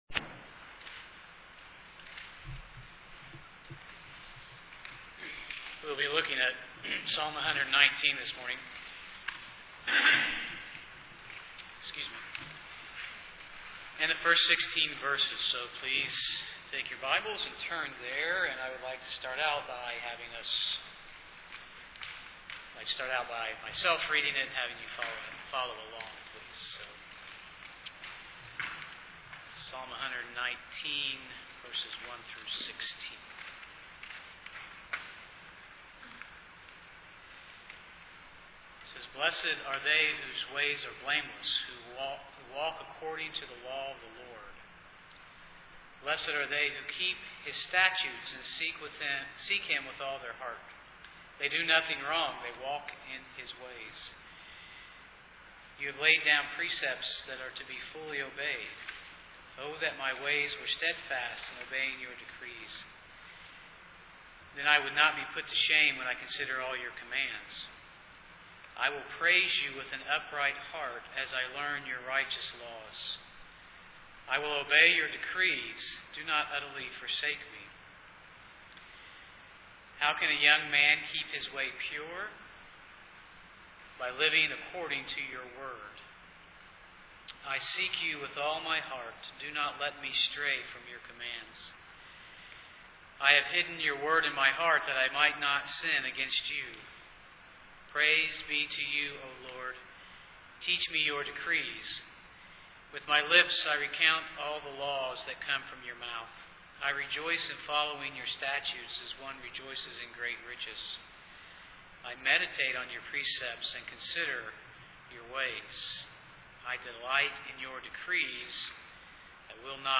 Psalm 119:1-16 Service Type: Sunday morning Bible Text